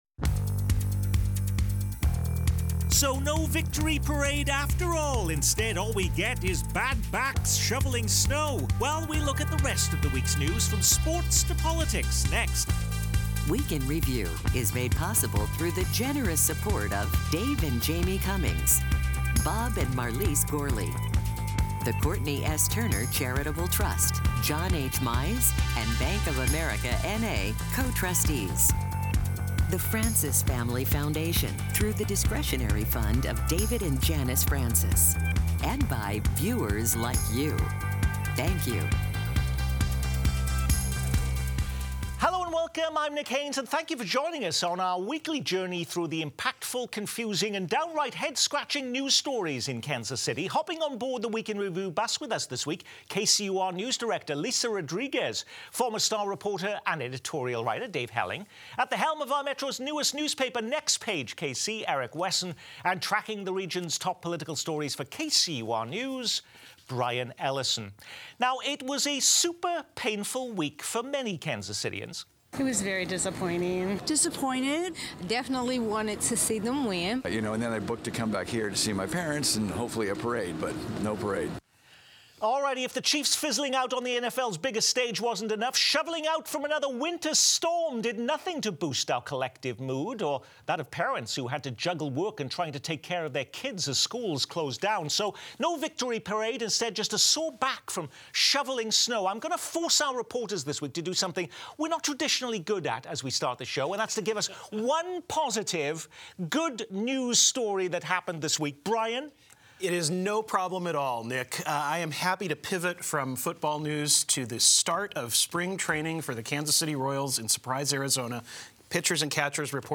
Kansas City Week In Review offers an in-depth view on the top stories of the week with newsmakers and guest experts sharing their insight and perspective.